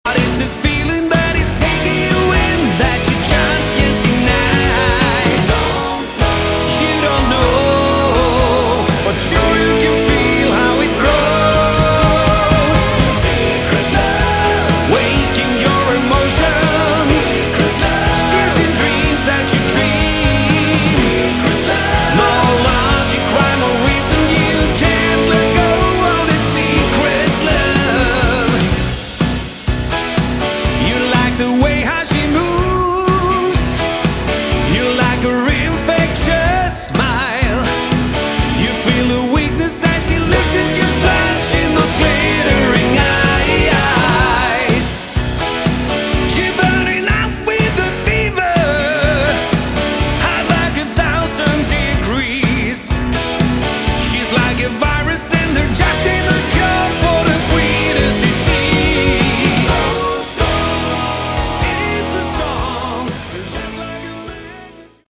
Category: AOR / Melodic Rock
vocals
guitars, bass, keys
keys, synths